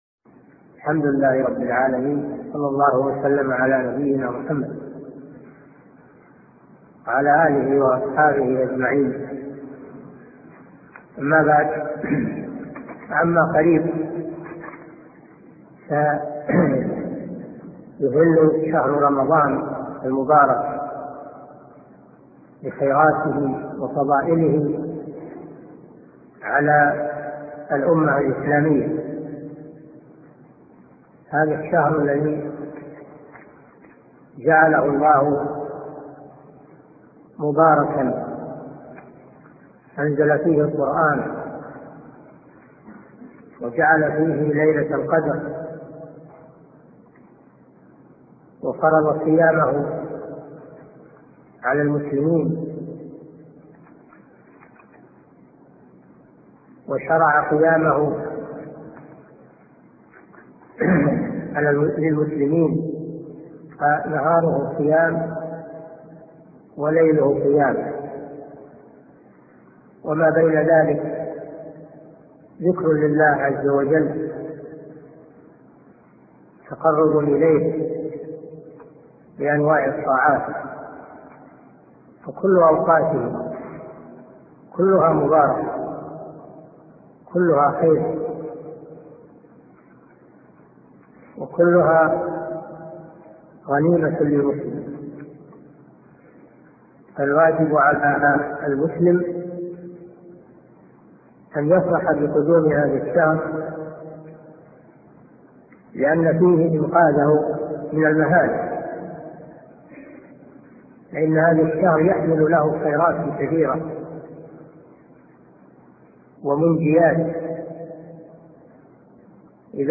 القسم: من مواعظ أهل العلم